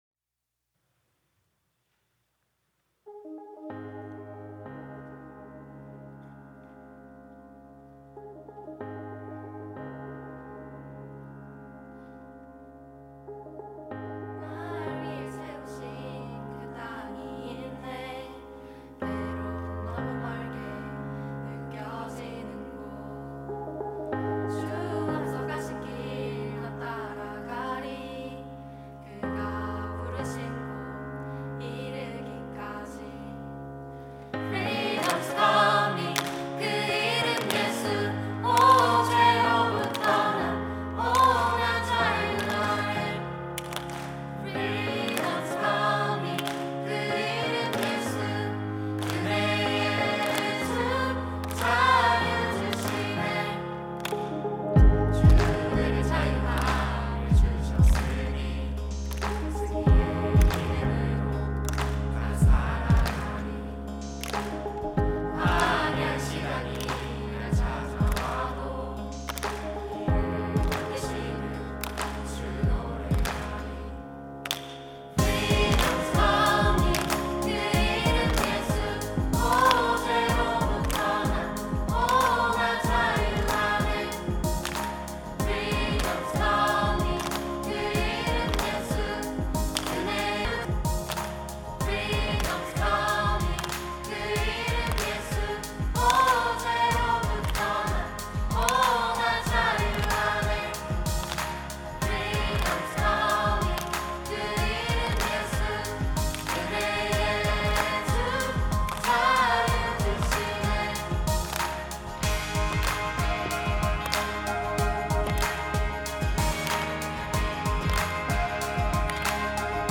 특송과 특주 - 자유의 이름
청년부 28기